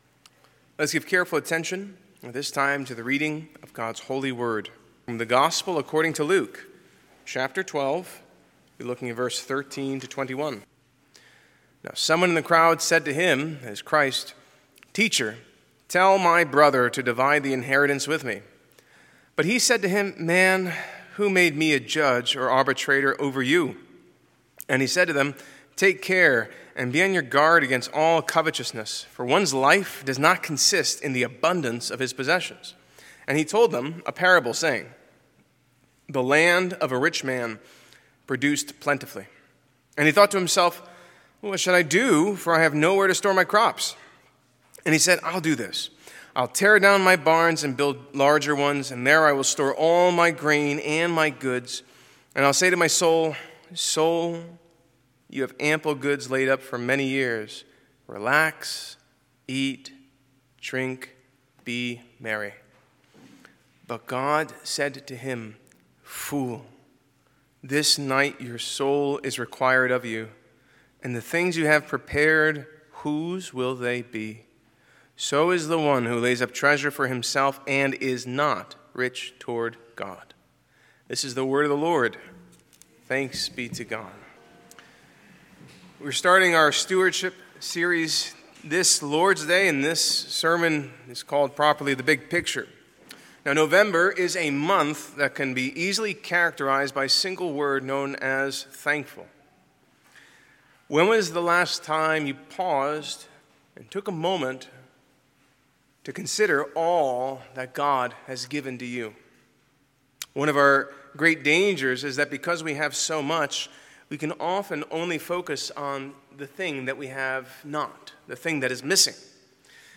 Passage: Luke 12:13-21 Service Type: Morning Worship